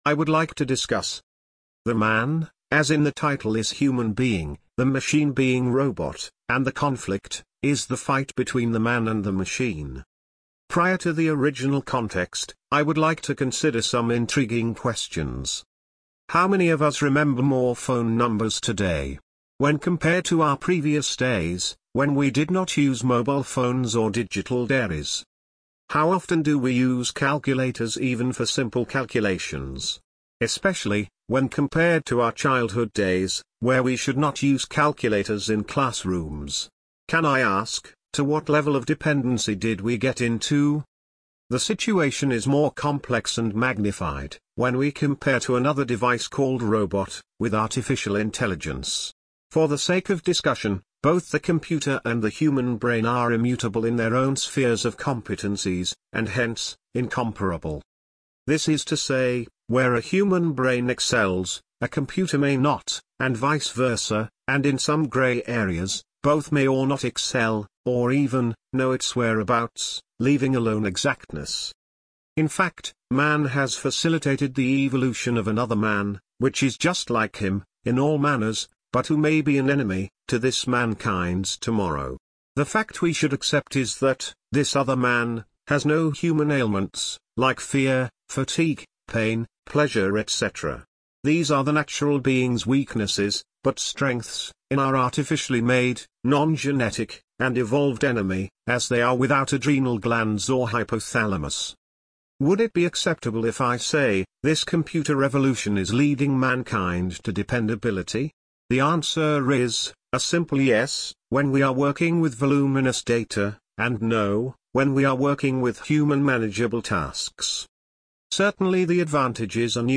Audio Version of this article
Many Thanks to Amazon Polly